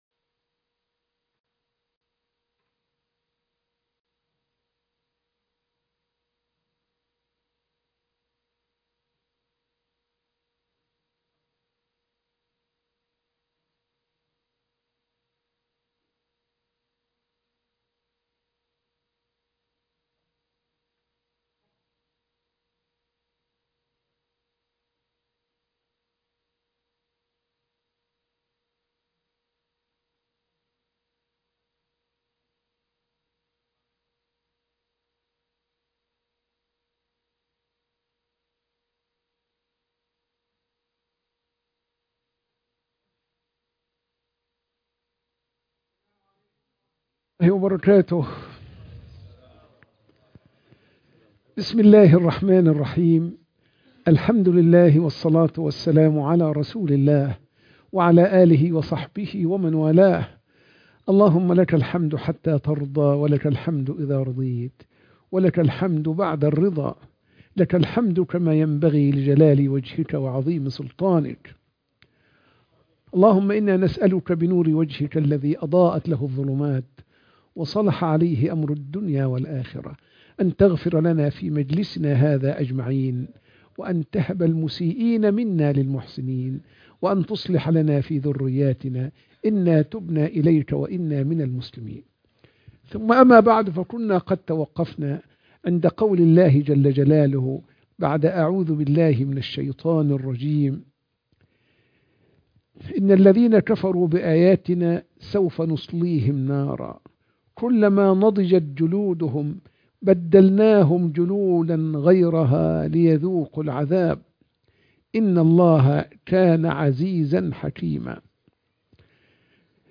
(3) تفسير سورة النساء - الآية 56 - بث مباشر